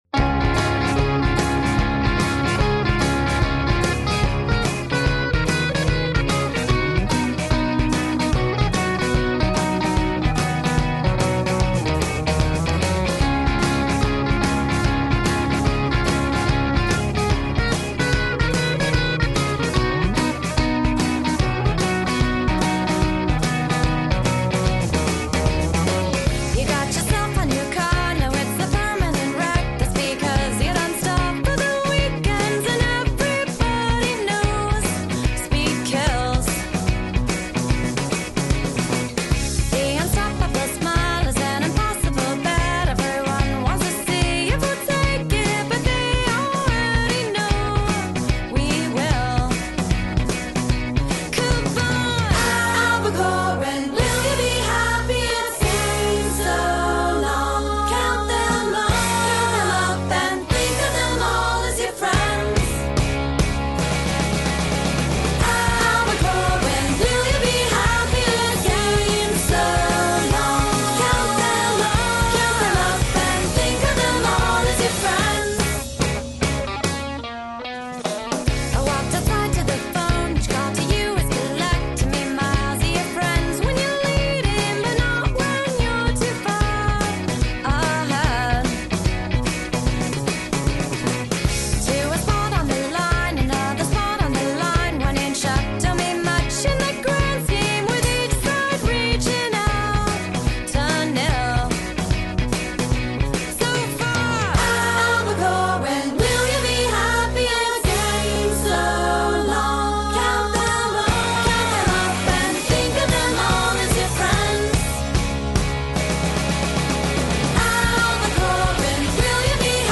gritty sing-song pop-rock